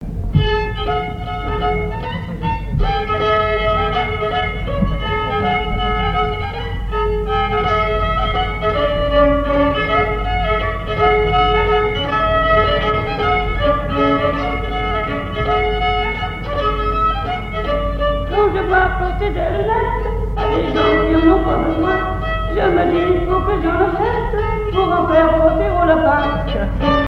Chants brefs - A danser
danse : mazurka
Assises du Folklore
Pièce musicale inédite